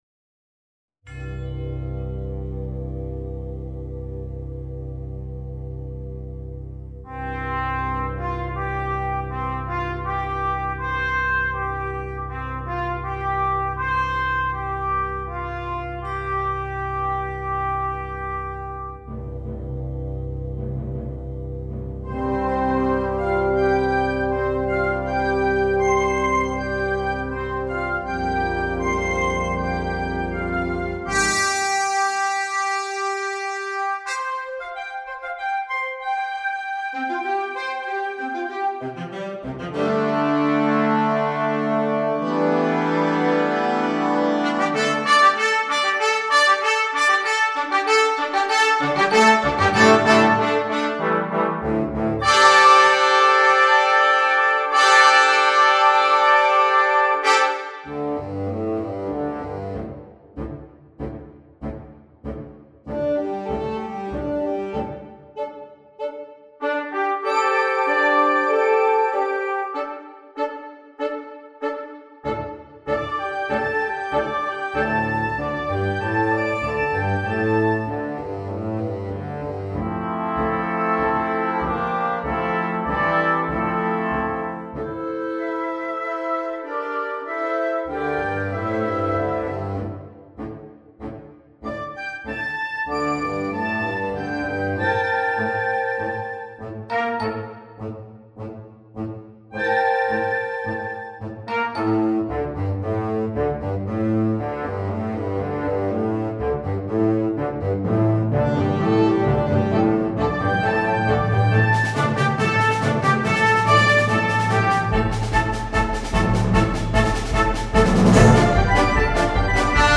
Genre: Band
Timpani [4 drums]
Percussion [3 players]: Snare Drum, Bass Drum, Crash Cymbals
Keyboard Percussion [1 player]: Xylophone, Vibraphone
Chimes